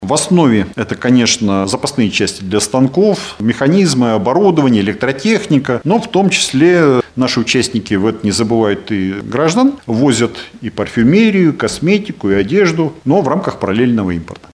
За 9 месяцев в регион привезли товары на общую сумму более 70 млн долларов. За аналогичный период прошлого года сумма была меньше — 38 миллионов, — рассказал начальник Уральского таможенного управления Алексей Фролов на пресс-конференции в агентстве ТАСС-Урал.